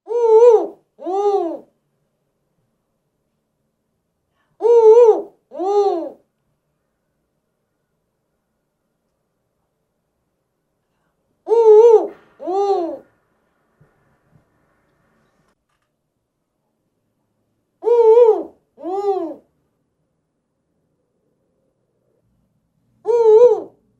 spotted-eagle-owl.mp3